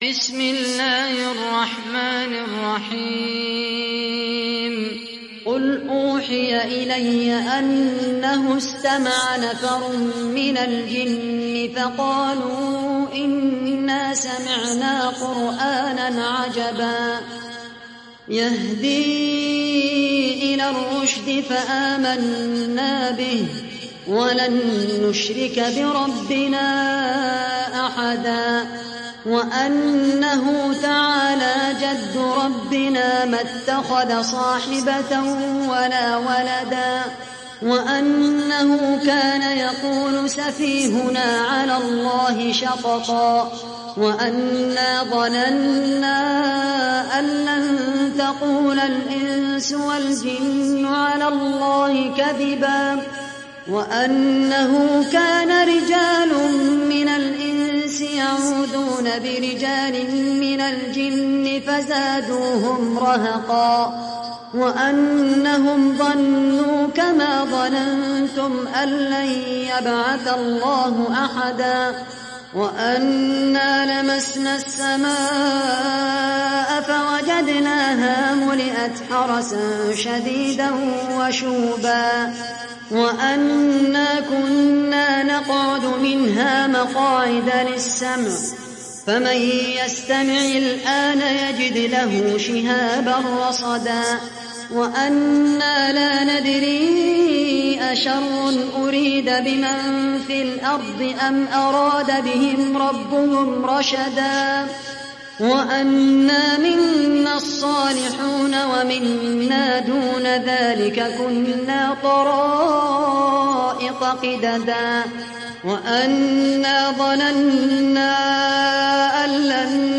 Surah Al Jinn Download mp3 Khaled Al Qahtani Riwayat Hafs from Asim, Download Quran and listen mp3 full direct links